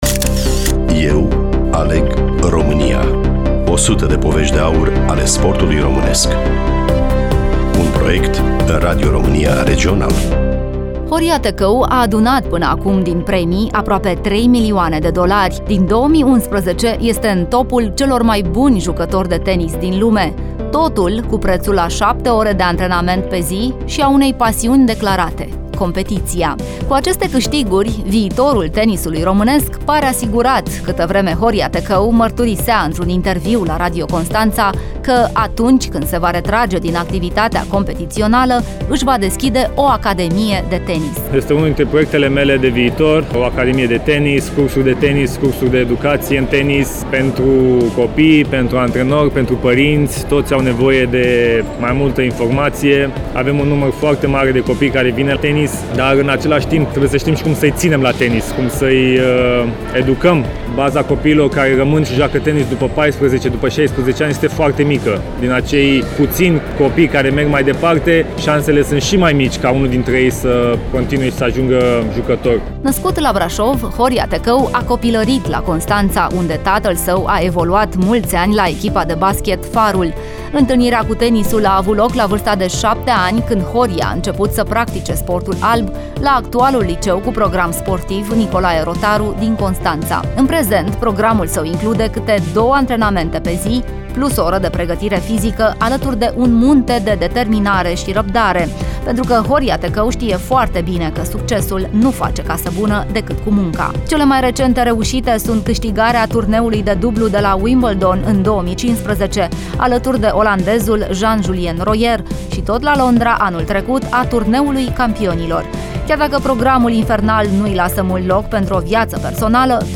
Voice over